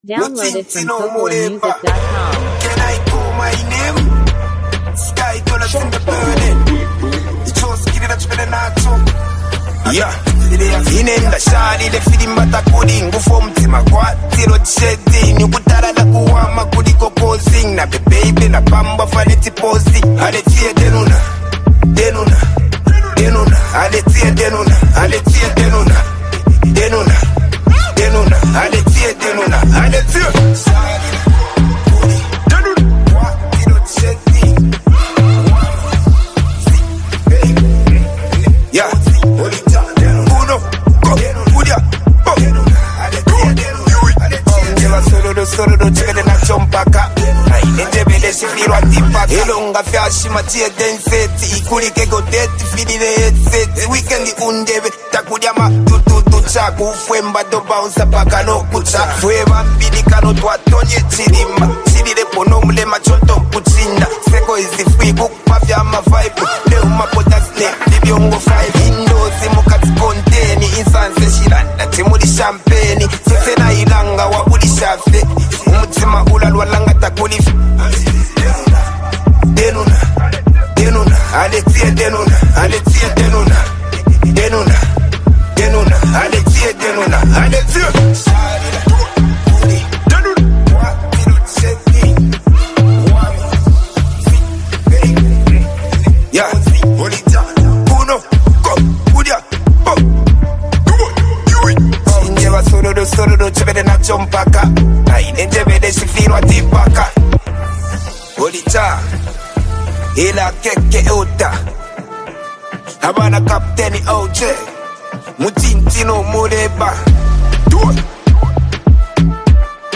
bold lyricism and a grounded delivery